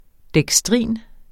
Udtale [ dεgsˈtʁiˀn ]